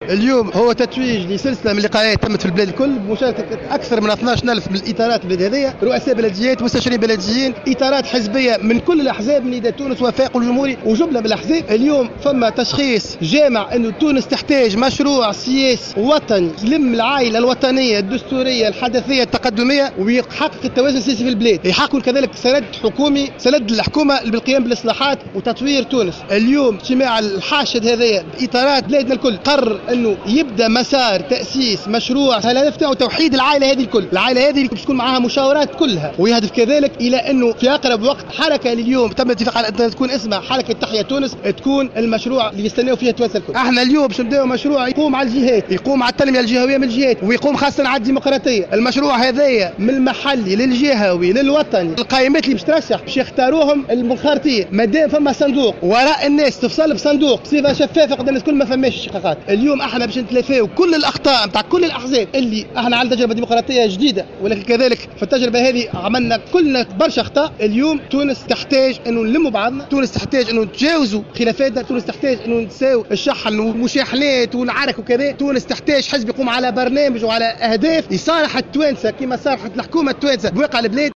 وقال في تصريح لمراسل "الجوهرة اف أم" على هامش اجتماع في المنستير إنه شارك في هذه اللقاءات أكثر من 12 الف اطار من رؤساء بلديات ومستشارين بلديين وإطارات حزبية من كل احزاب تونس من نداء تونس وافاق تونس والجمهوري..